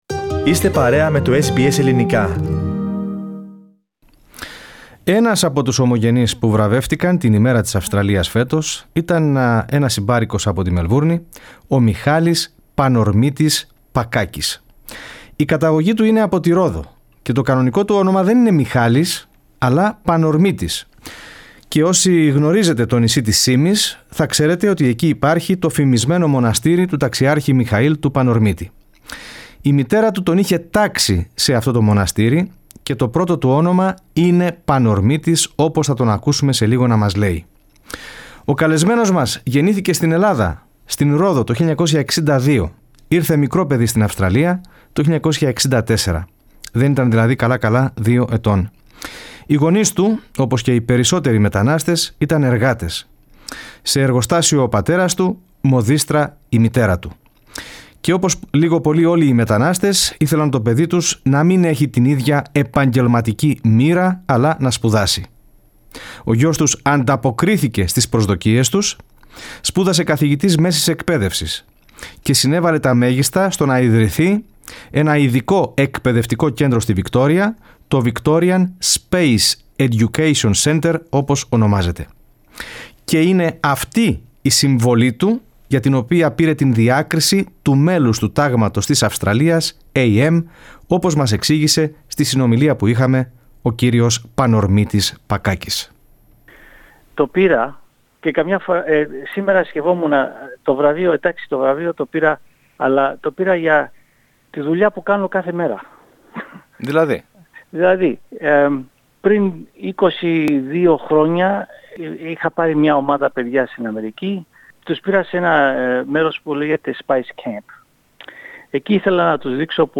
συνέντευξή